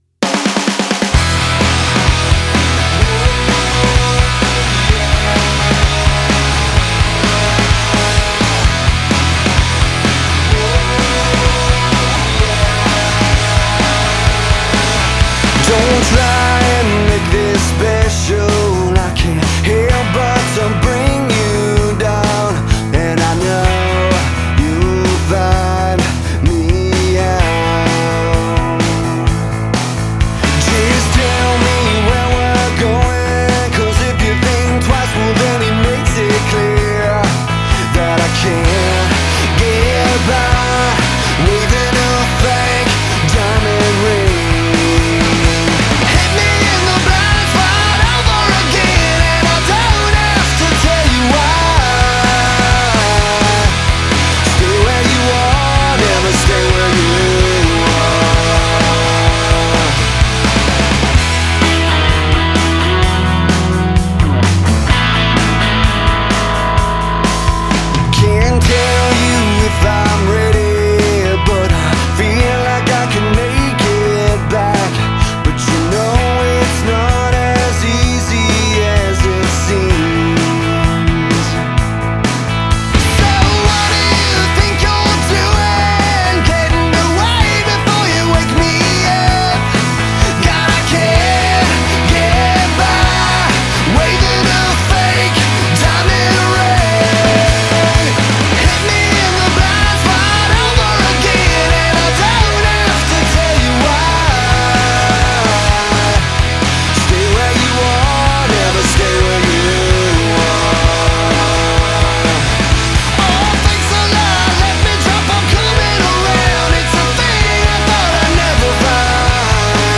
hard alternative rock